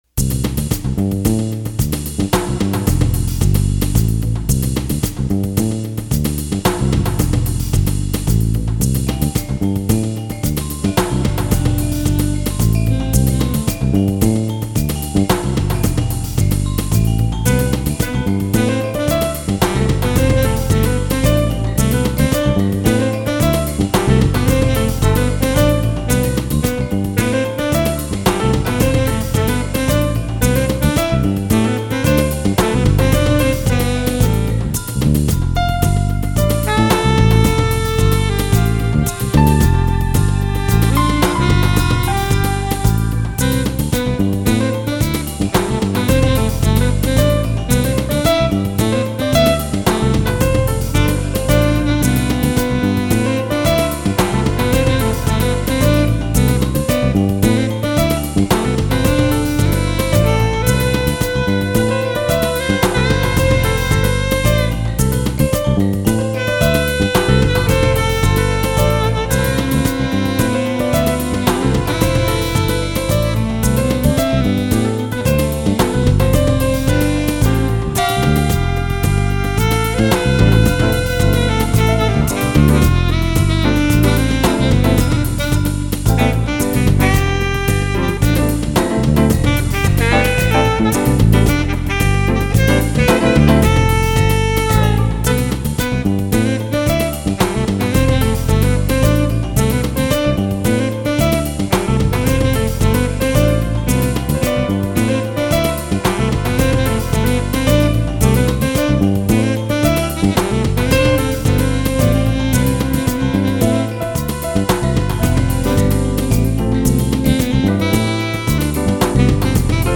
improvisational music
improvised monoloques, percussion
guitar
guitar, sax, keys, bass, drum prog,